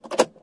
燃气灶具点火器
描述：煤气灶点火器的单声道录音
标签： 火焰 点火器